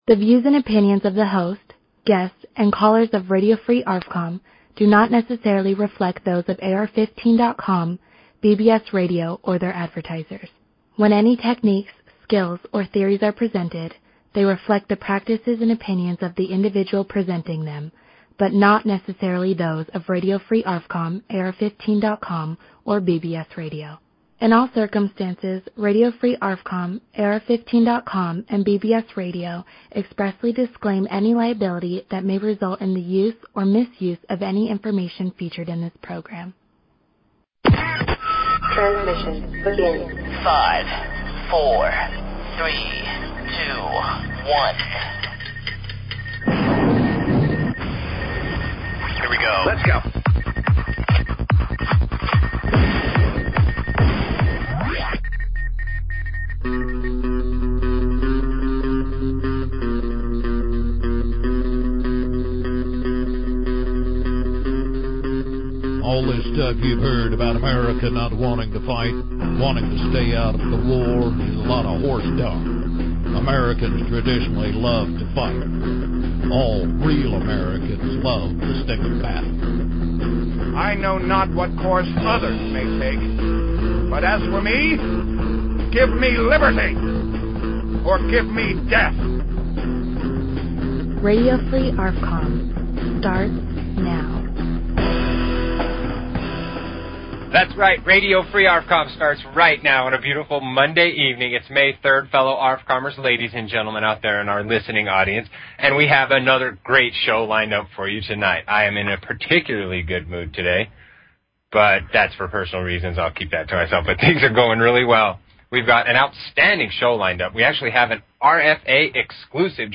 Talk Show Episode, Audio Podcast, Radio_Free_ARFCOM and Courtesy of BBS Radio on , show guests , about , categorized as